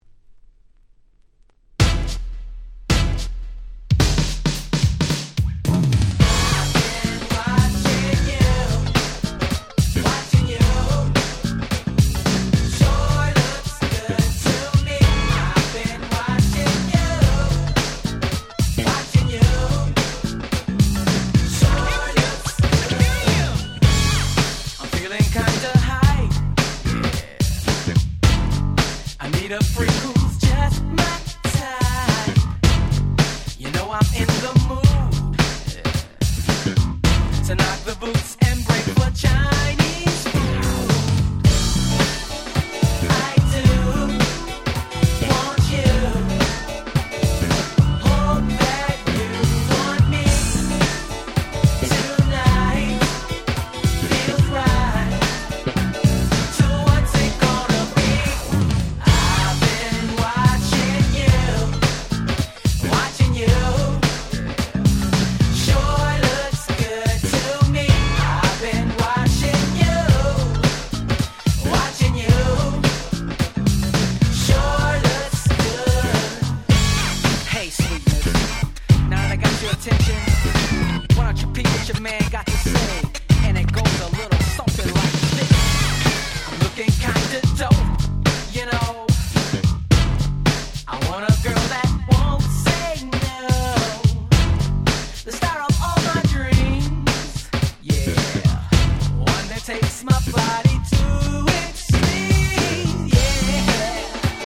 92' Very Nice New Jack Swing / R&B !!
90's ニュージャックスウィング ハネ系